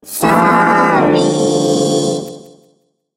evil_rick_kill_vo_05.ogg